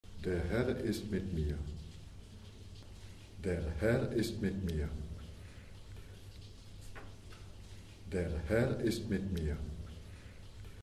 Prononciation
Der Herr ist mit mir - normal.mp3